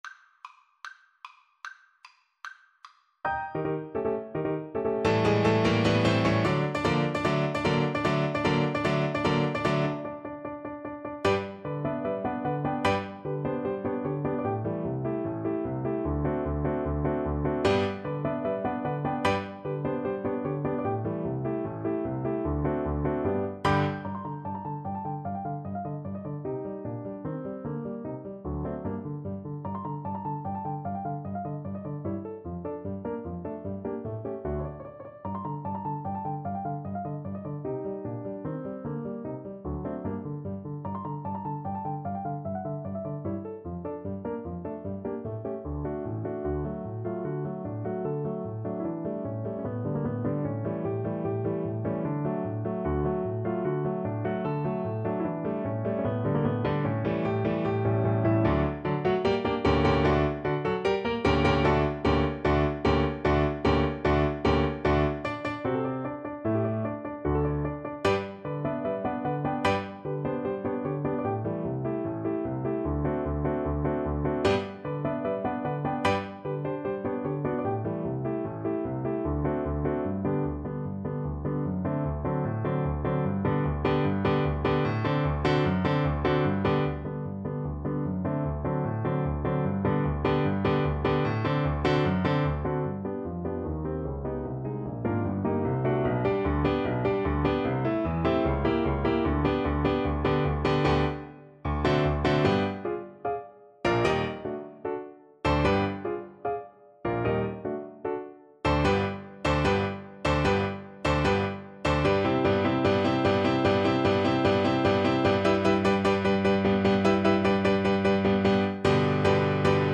Play (or use space bar on your keyboard) Pause Music Playalong - Piano Accompaniment Playalong Band Accompaniment not yet available transpose reset tempo print settings full screen
2/4 (View more 2/4 Music)
Allegro vivacissimo ~ = 150 (View more music marked Allegro)
G major (Sounding Pitch) (View more G major Music for Flute )
Classical (View more Classical Flute Music)